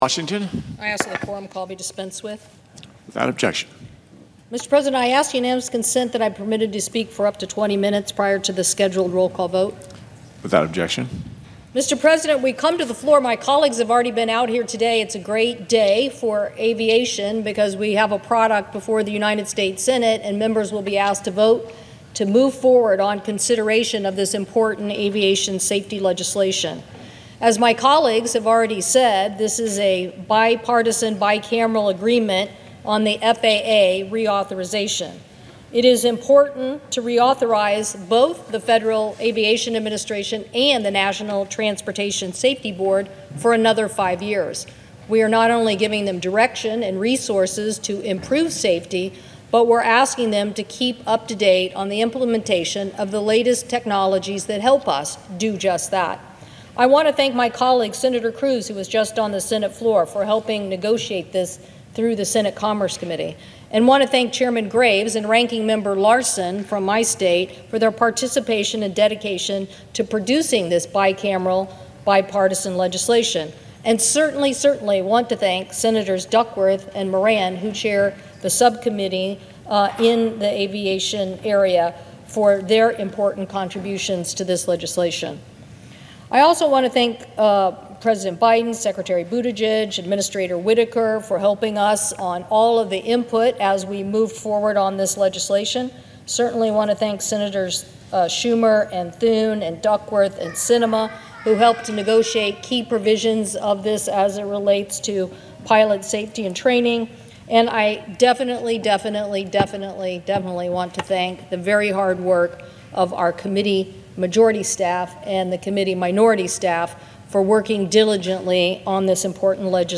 5124-faa-reauthorization-speech-audio&download=1